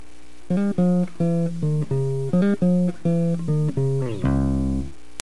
Final: bass
si_se_acabo_bassfinal.mp3